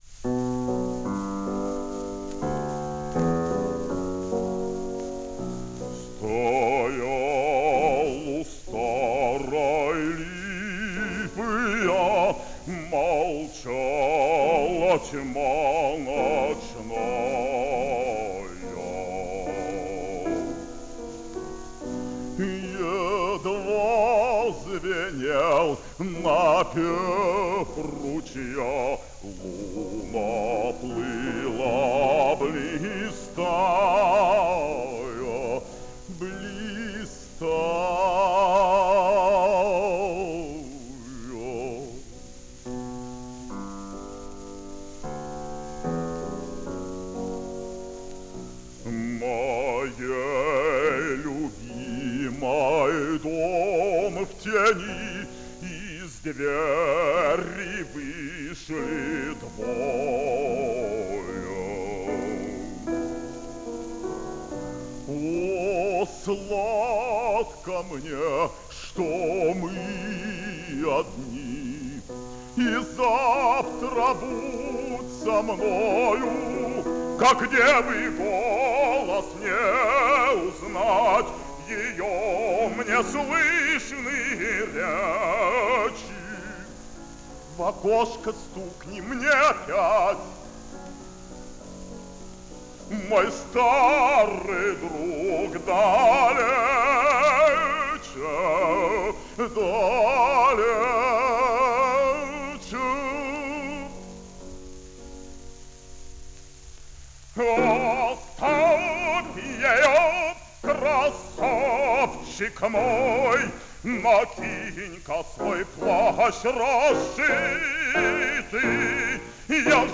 фп.) - Измена (И.Брамс - К.Лекме) (1951)